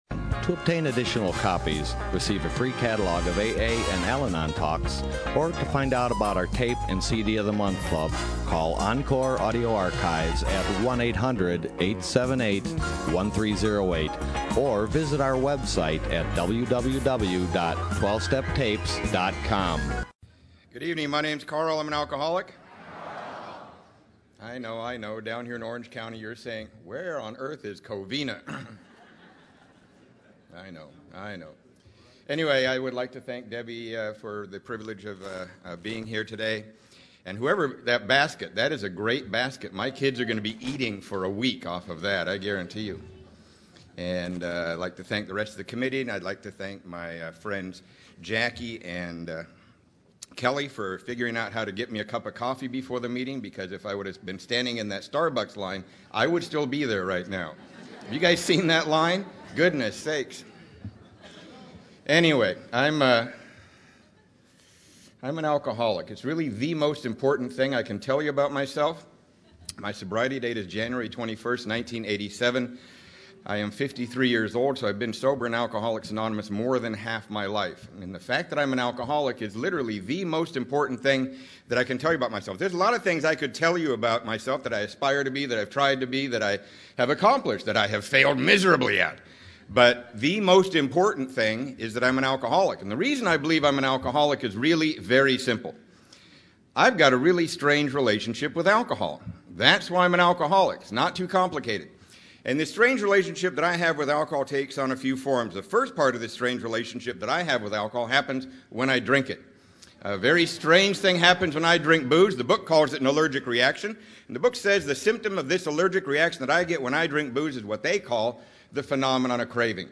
Orange County AA Convention 2015